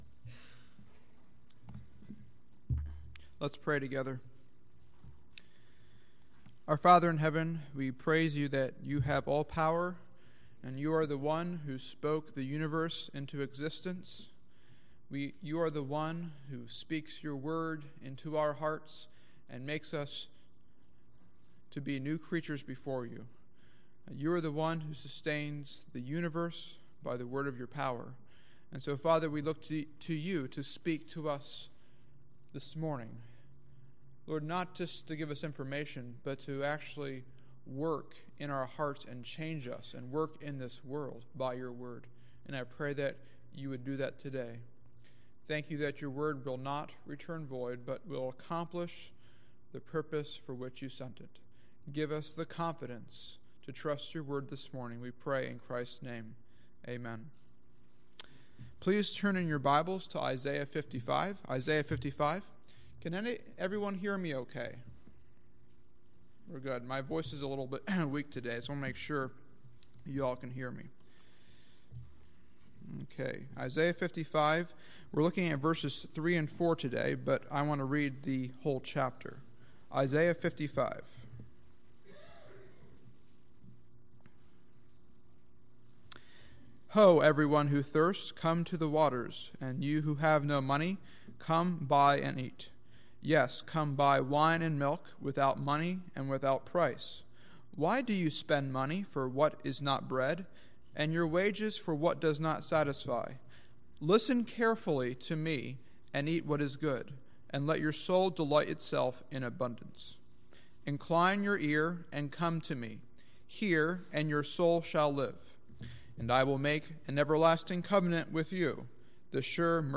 Sermons List